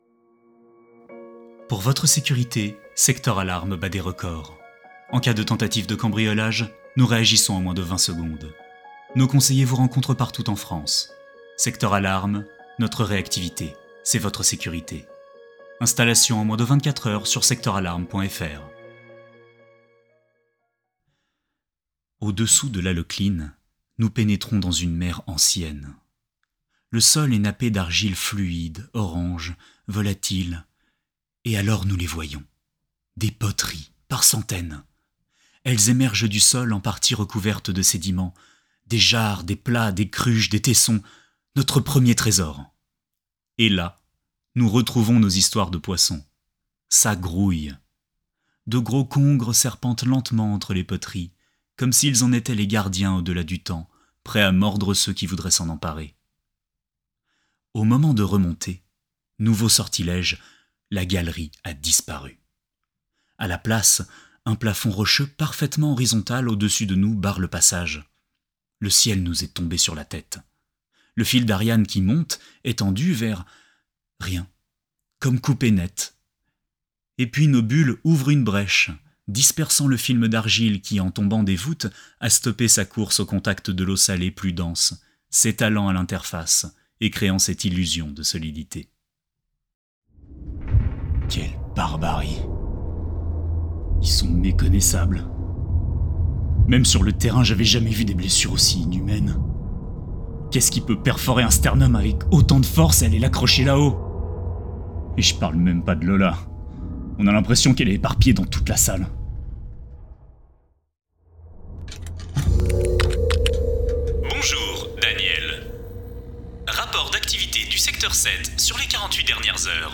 Bandes-son
Voix off
20 - 45 ans - Baryton
Doublage, voix-off, voiceover, livre audio